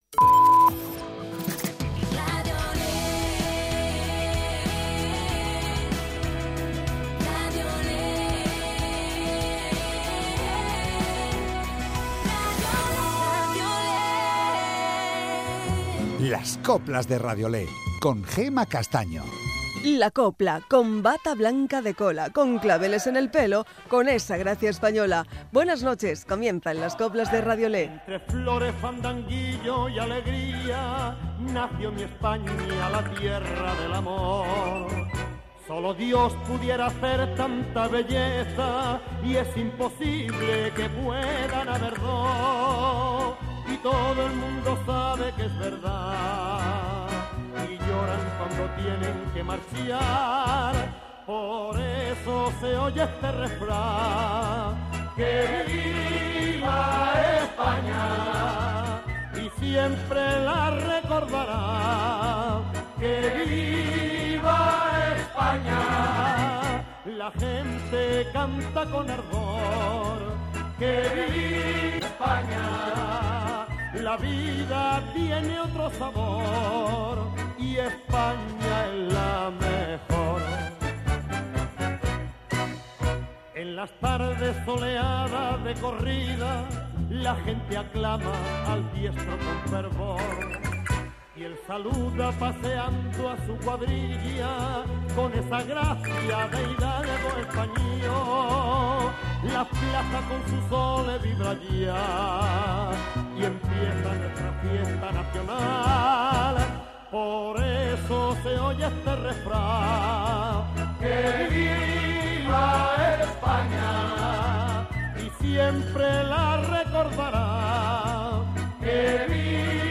Las mejoras coplas de hoy y de siempre